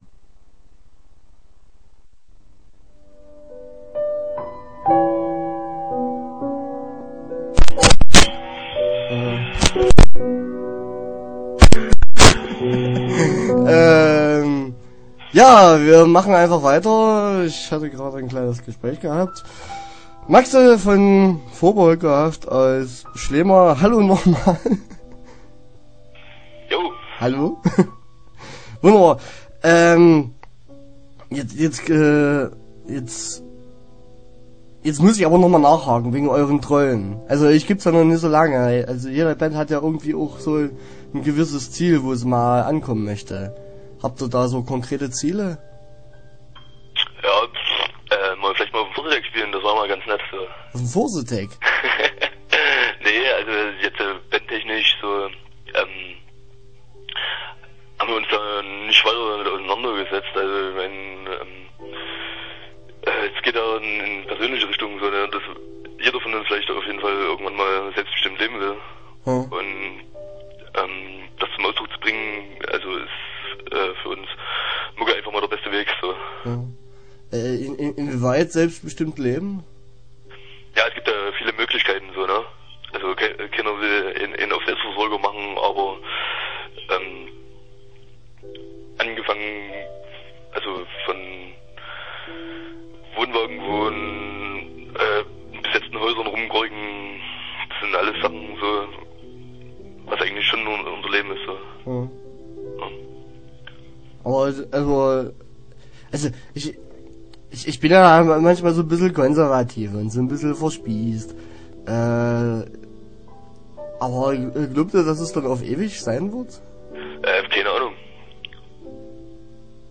Interview Teil 1 (10:21)